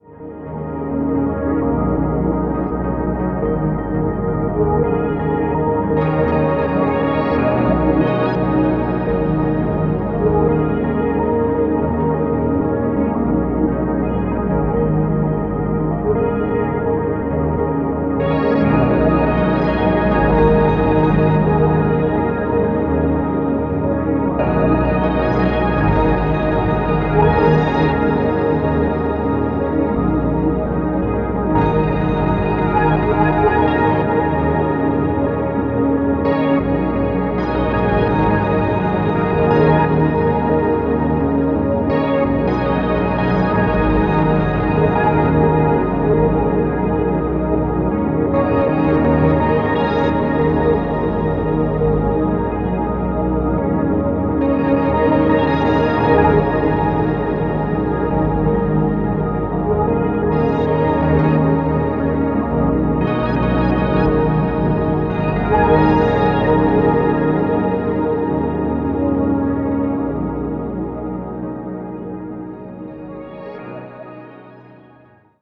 Here’s an ambient analog synth looper jam: